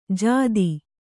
♪ jādi